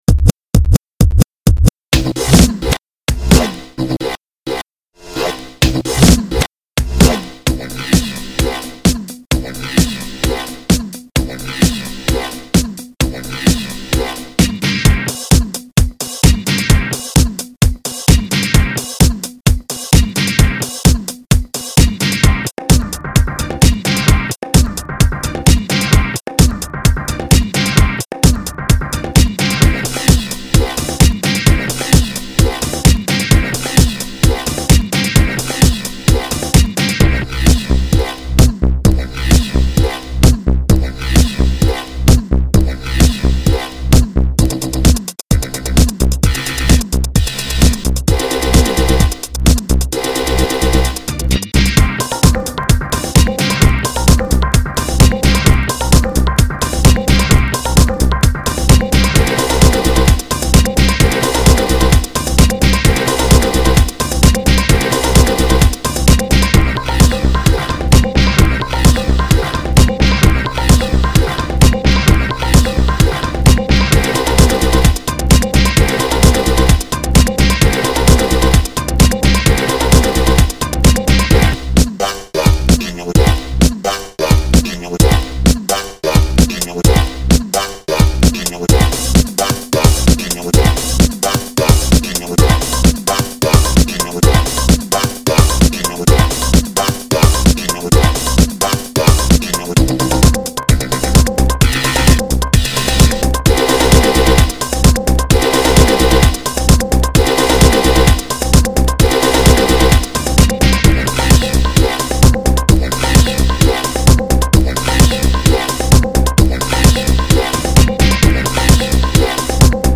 PSP Rhythm is a homebrew drum machine for the Sony Playstation Portable.